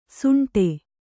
♪ suṇṭe